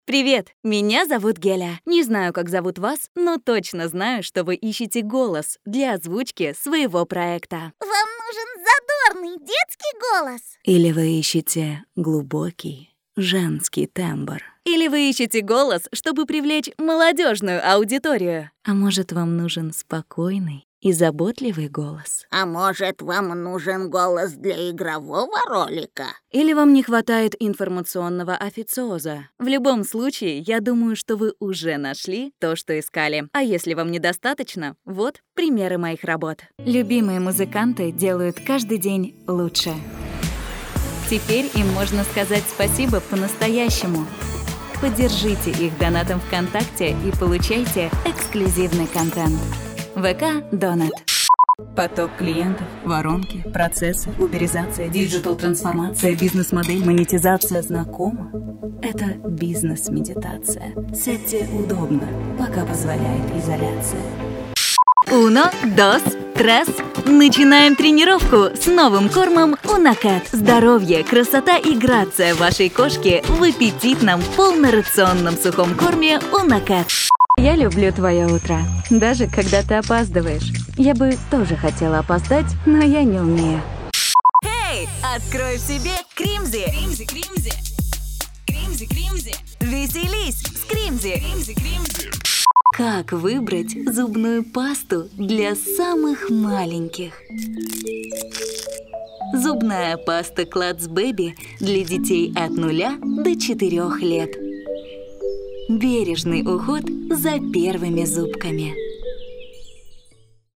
Commercial, Young, Natural, Friendly, Corporate
Audio guide
- native russian speaker without region accent